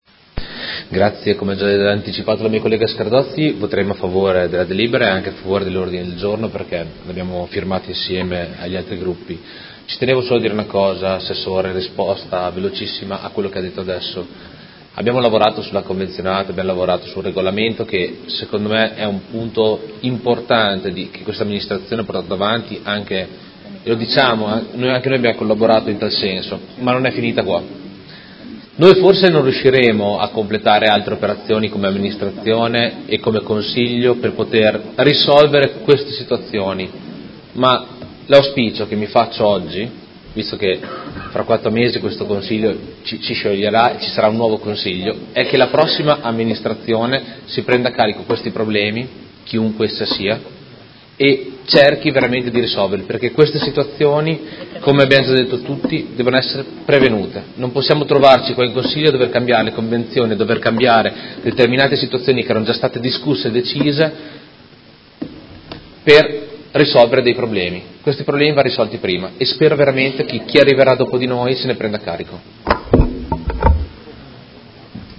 Seduta del 17/01/2019 Dichiarazioni di voto.